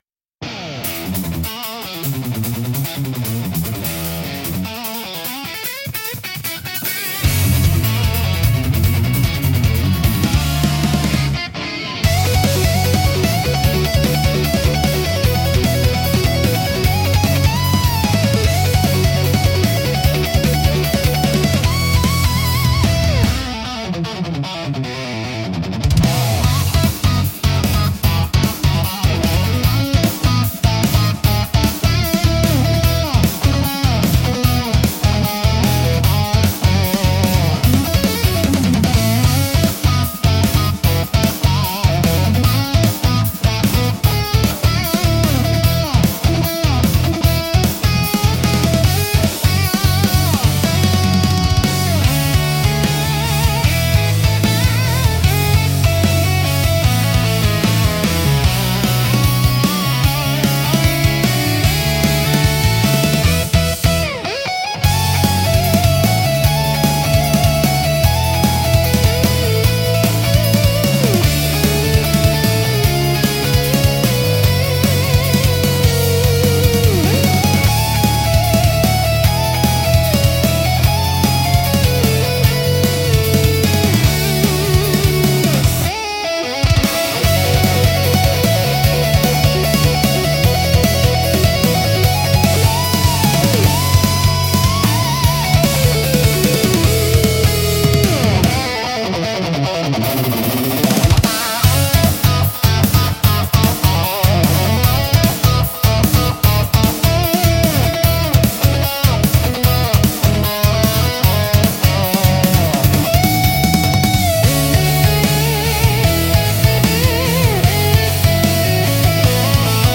BGMセミオーダーシステムドライブは、クールで疾走感あふれるロック系のオリジナルジャンルです。